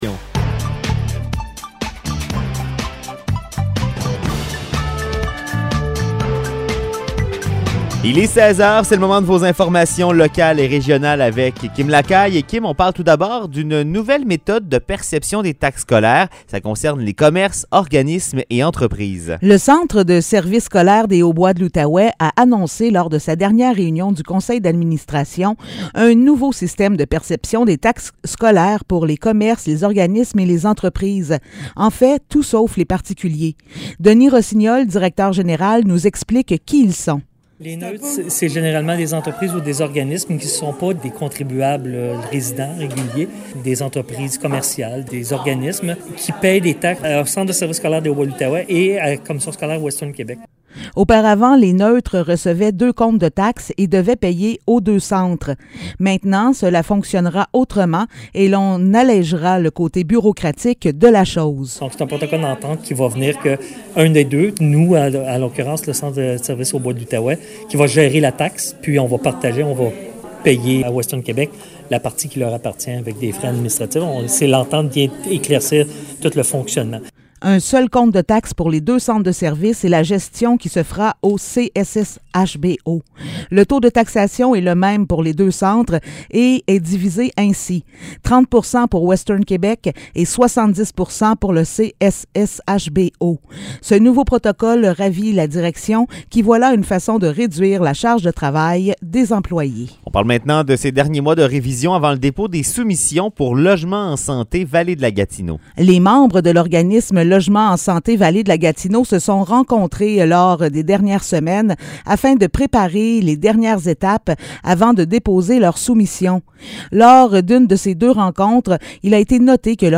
Nouvelles locales - 5 avril 2022 - 16 h